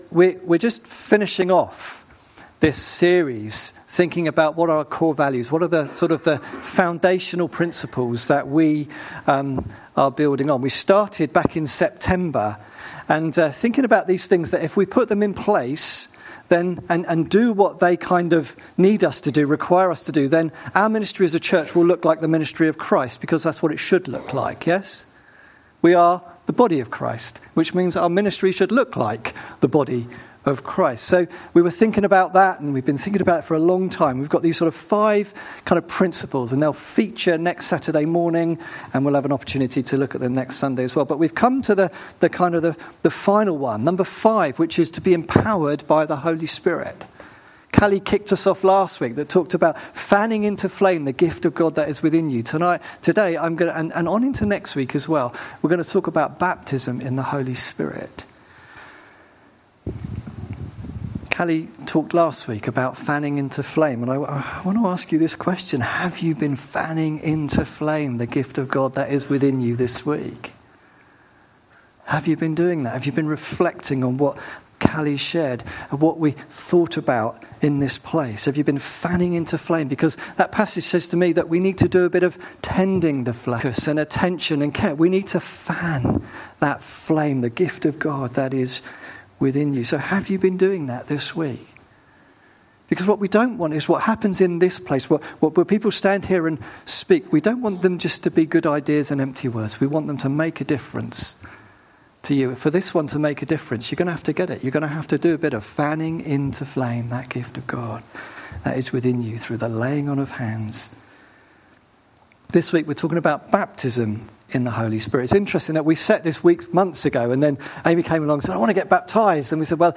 Baptism Service – Empowered by the Spirit
17 November sermon (26 minutes)